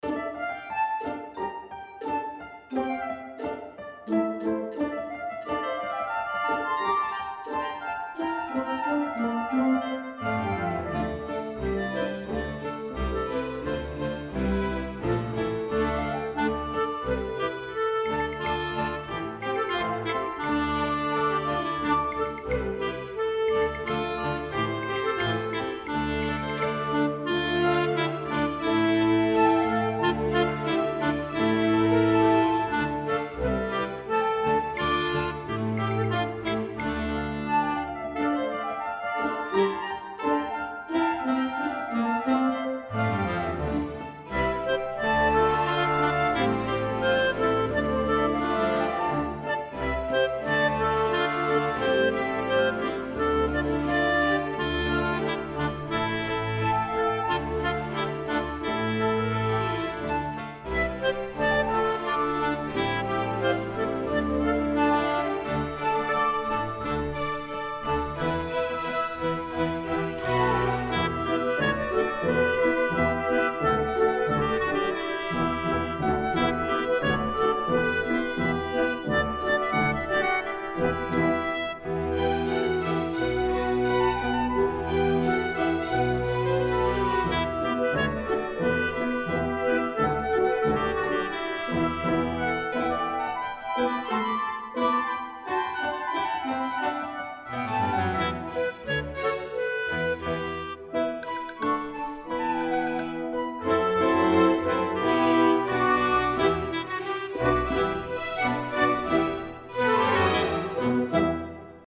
Arrangements for Solo Instrument to Full Orchestra
Voicing: CelloDblBs